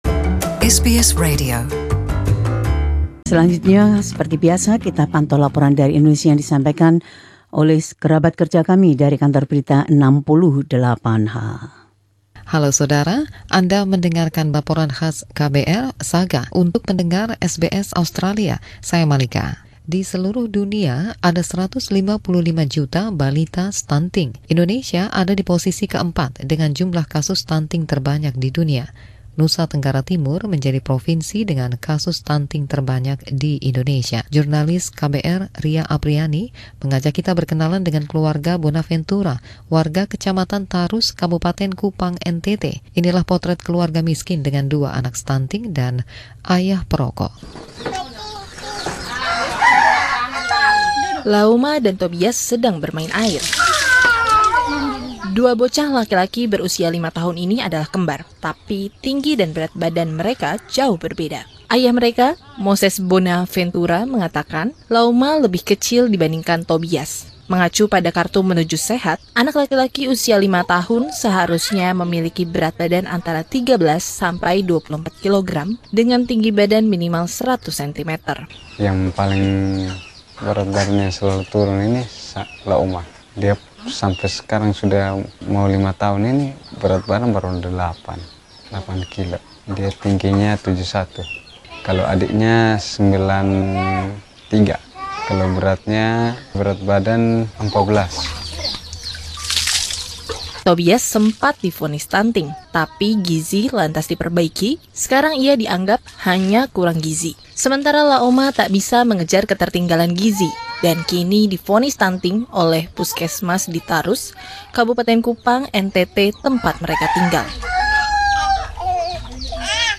This special report from the team at KBR 68 H describes one of the most serious problems confronting development in Indonesia.